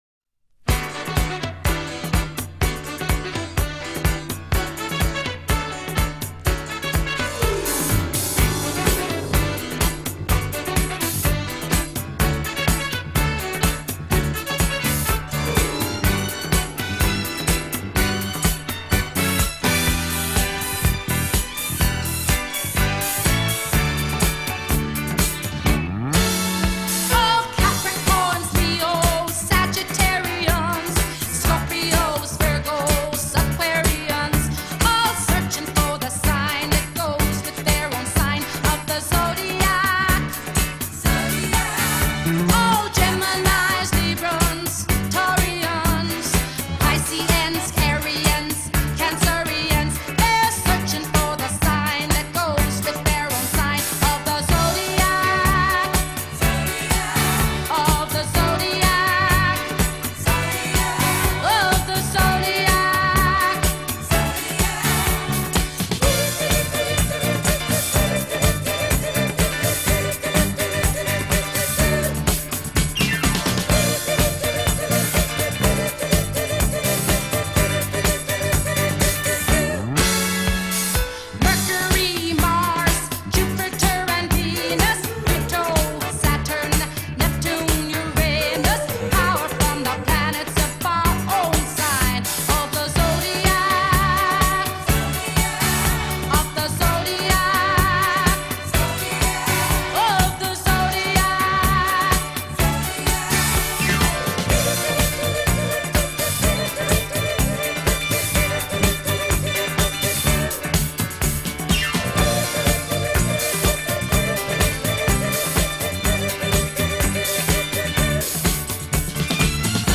It’s a mood changer.
perfect disco-era pop recording